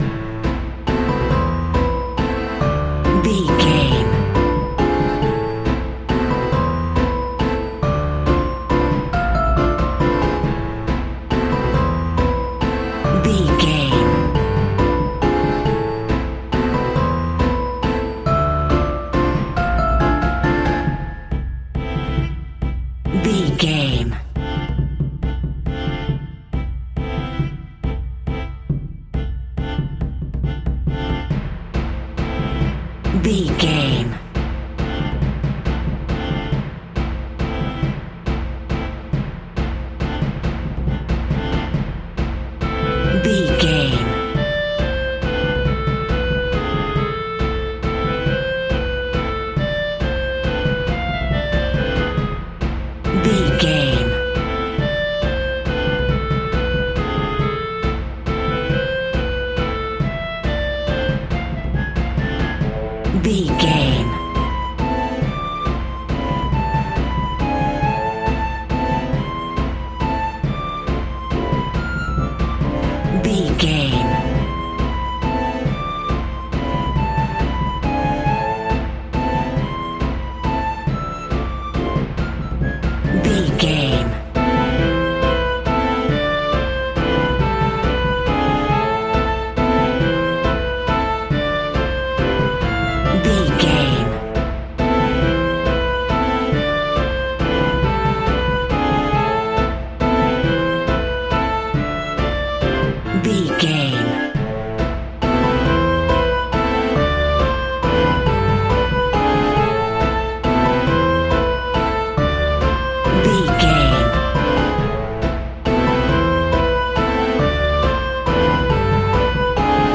Ballroom Horror.
Aeolian/Minor
ominous
haunting
eerie
piano
strings
percussion
orchestra